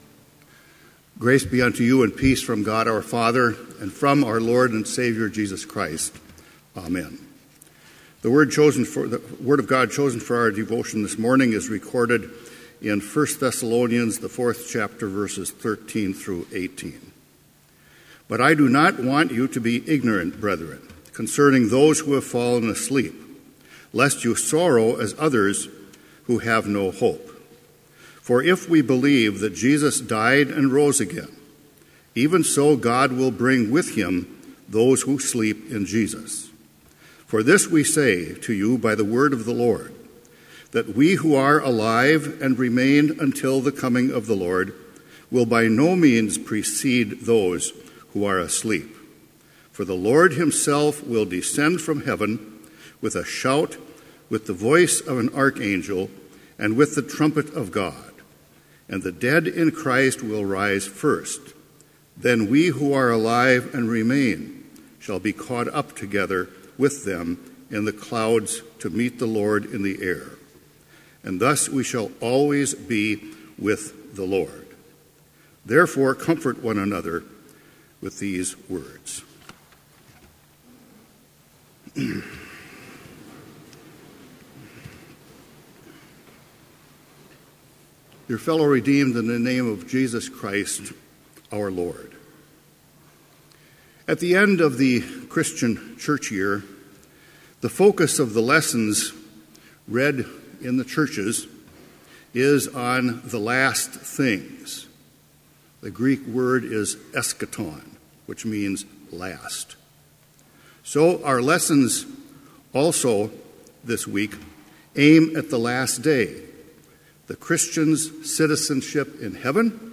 Complete Service
• Prelude
• Hymn 533, vv. 1-4, Preserve Thy Word, O Savior
• Devotion
This Chapel Service was held in Trinity Chapel at Bethany Lutheran College on Wednesday, November 9, 2016, at 10 a.m. Page and hymn numbers are from the Evangelical Lutheran Hymnary.